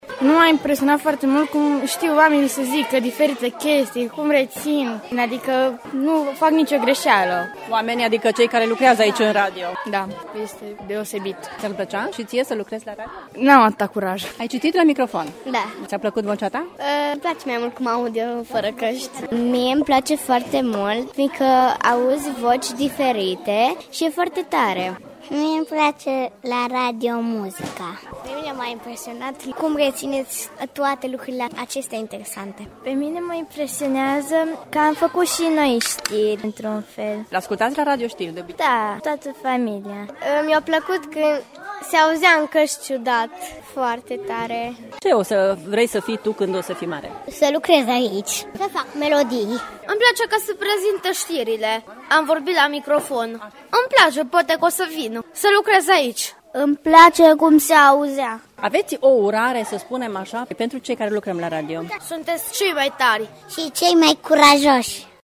Copiii și-au testat calitățile vocale, au creat știri și au aflat totul despre munca de radio: